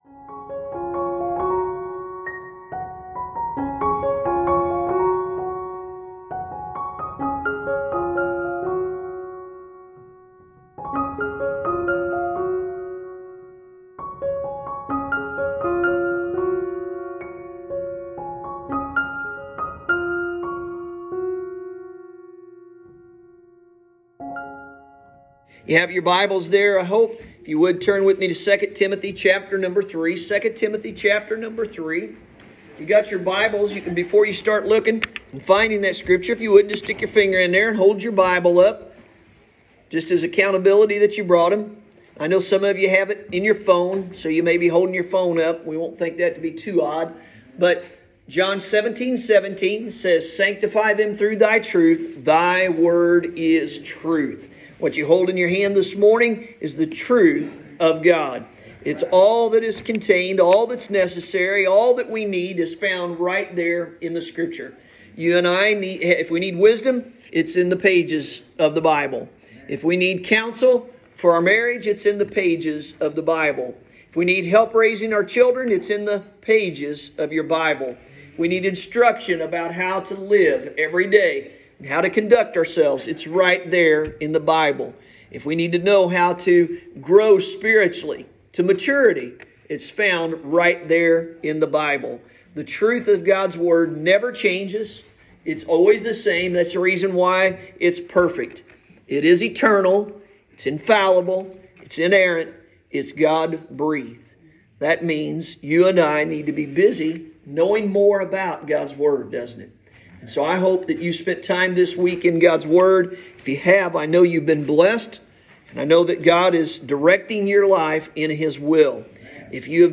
Sunday Morning – January 24, 2021